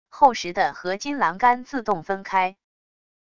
厚实的合金栏杆自动分开wav音频